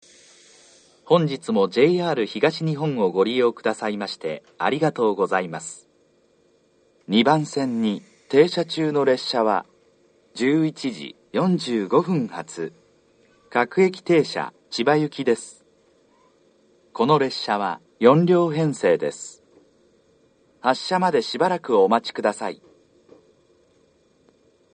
２番線上り出発予告放送 11:45発各駅停車千葉行（４両）の放送です。